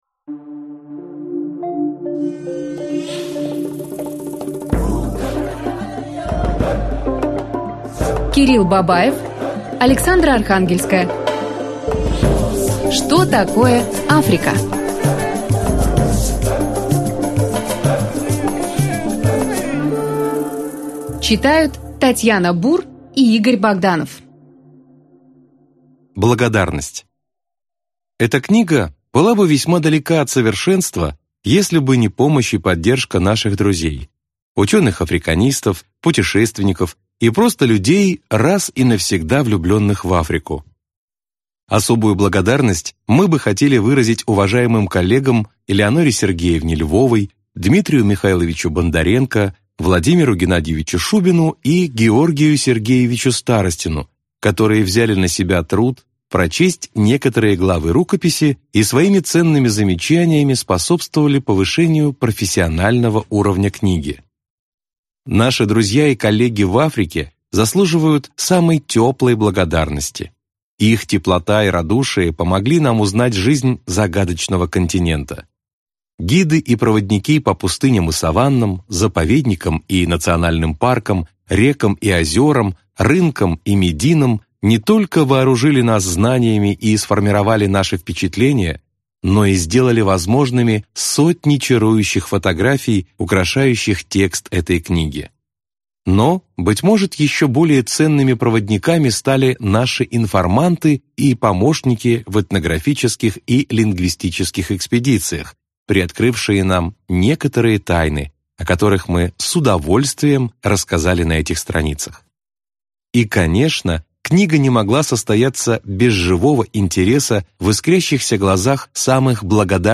Аудиокнига Что такое Африка | Библиотека аудиокниг